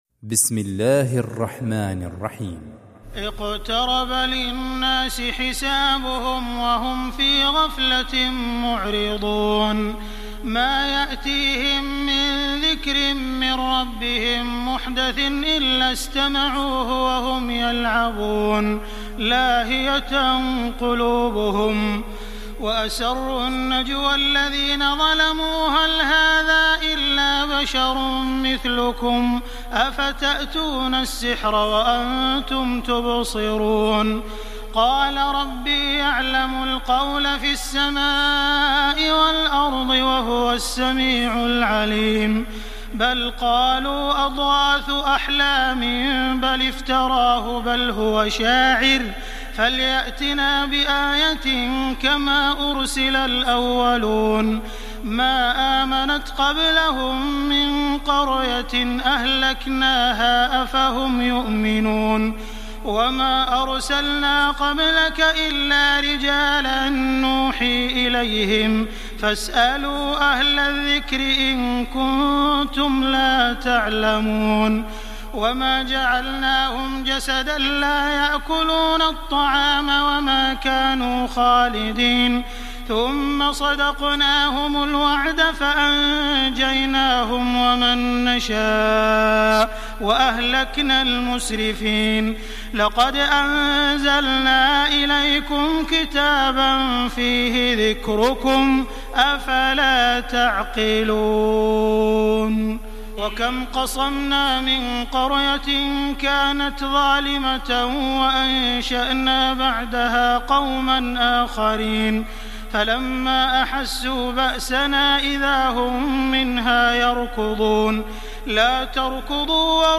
استمع الى باقي السور للقارئ عبد المحسن القاسم